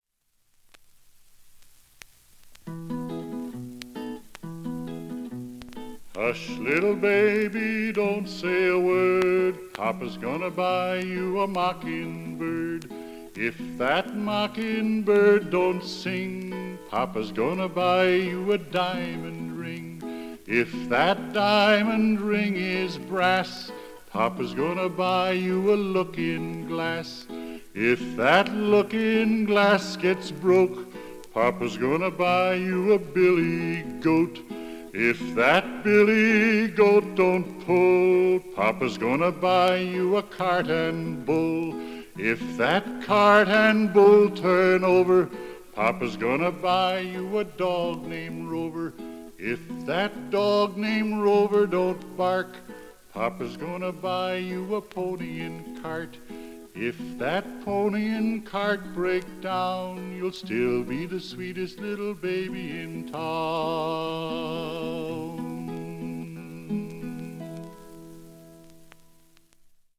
englisches Kinderlied